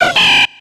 Cri de Poussifeu dans Pokémon X et Y.